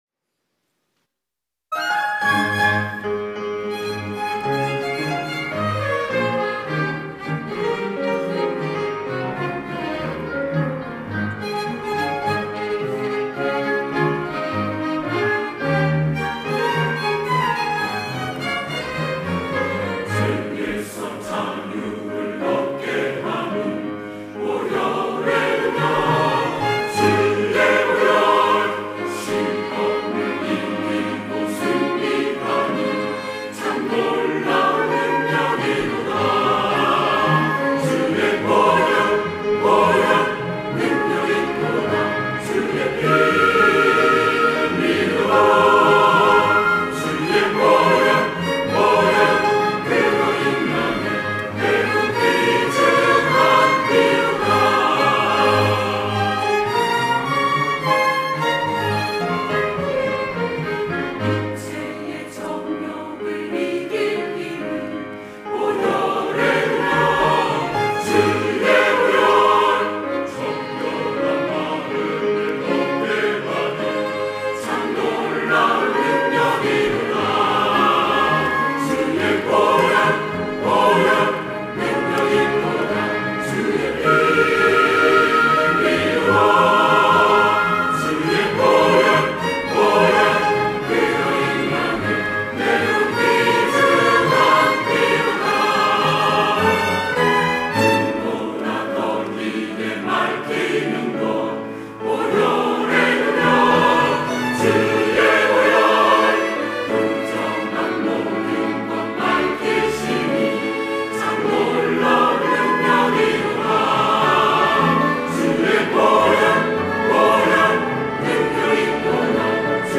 할렐루야(주일2부) - 죄에서 자유를 얻게 함은
찬양대